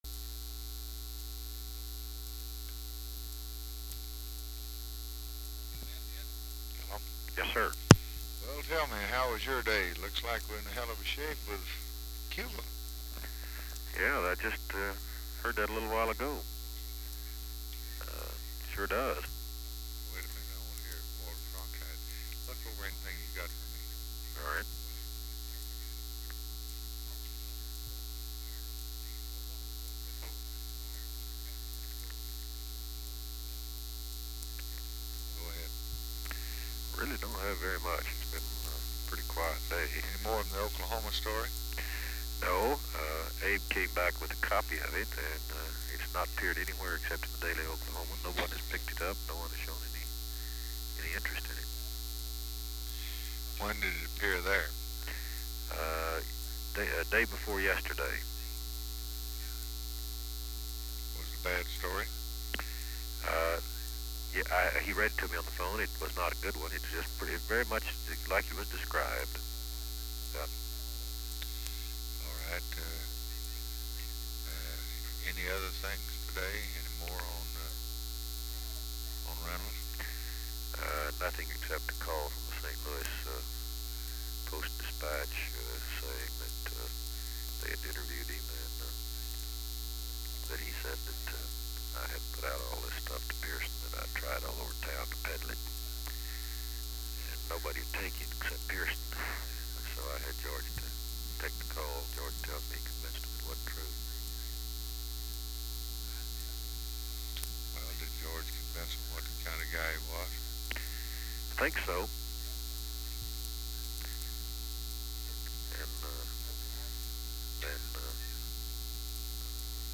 Conversation with WALTER JENKINS, February 6, 1964
Secret White House Tapes